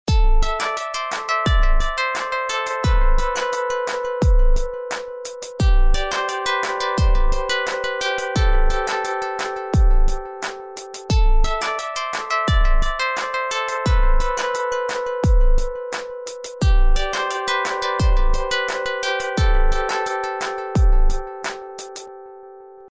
/262kbps) Описание: Мелодия на звонок.